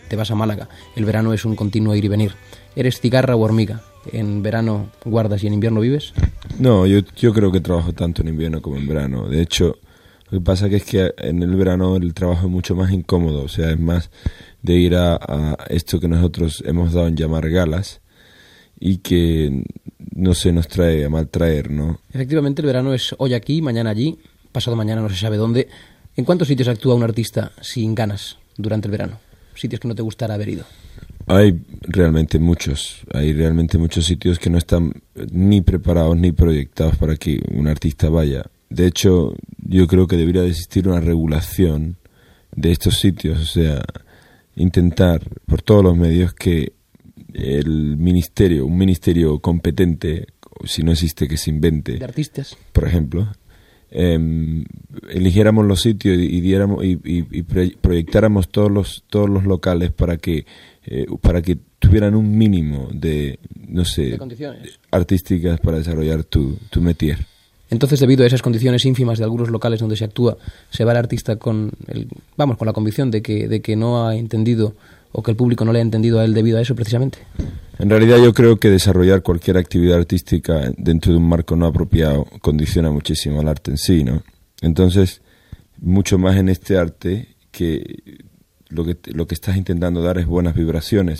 Entrevista al cantant Miguel Ríos sobre les gires d'estiu